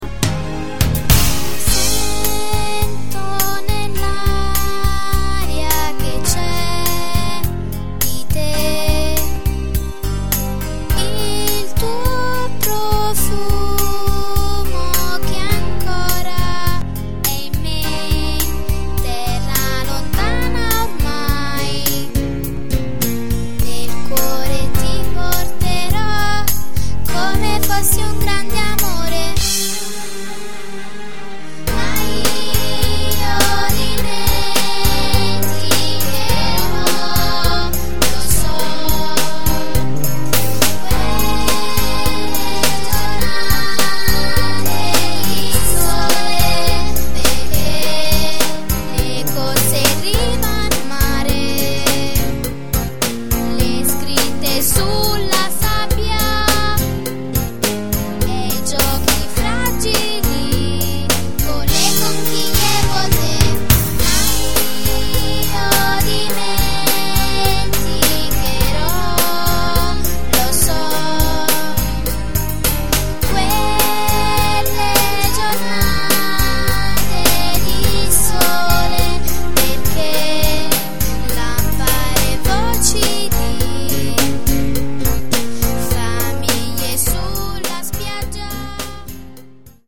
CANZONCINE